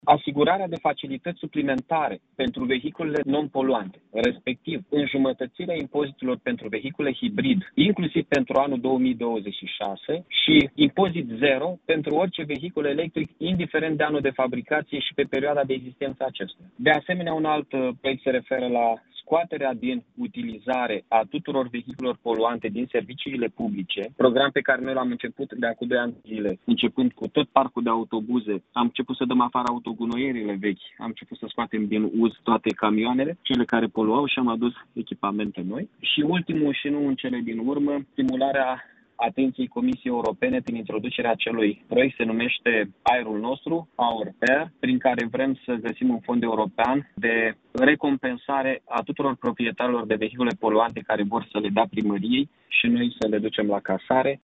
Primarul Iaşului, Mihai Chirica, a mai enumerat şi o serie de măsuri complementare pe care muncipalitatea le are în vedere: